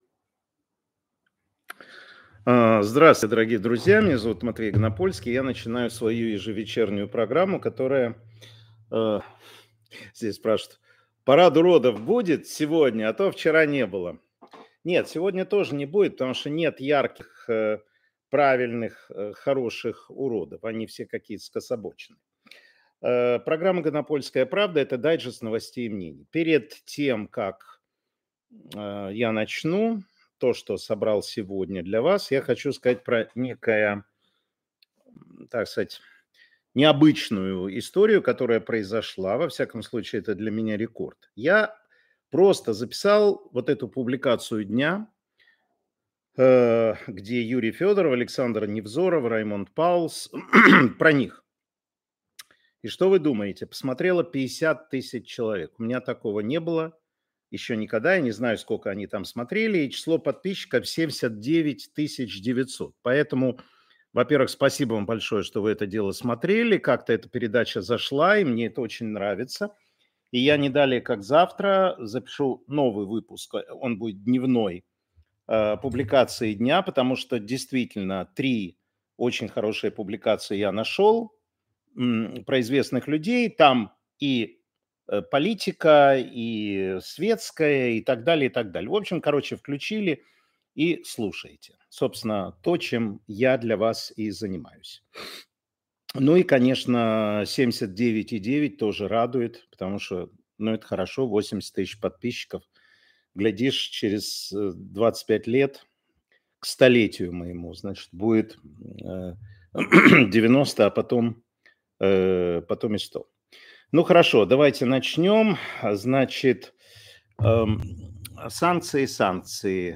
Эфир Матвея Ганапольского.